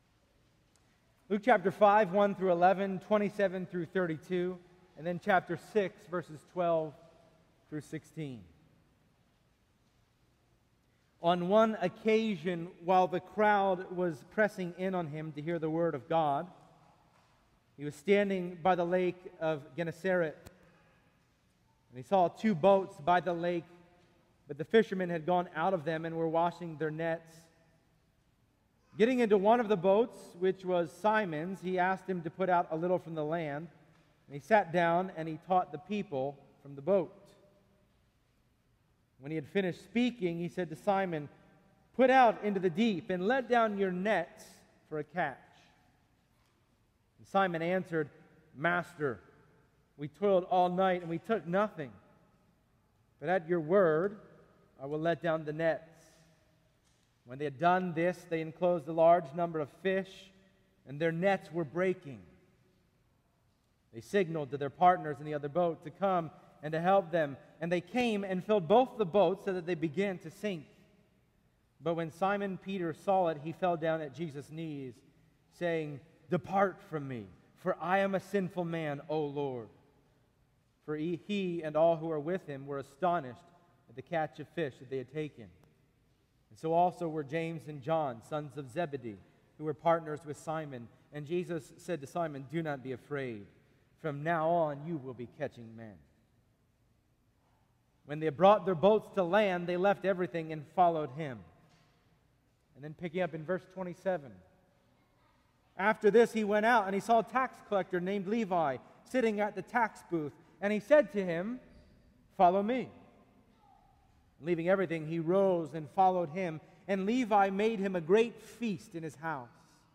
Passage: Luke 5:1-11, Luke 5:27-32, Luke 6:12-16 Service Type: Sunday Morning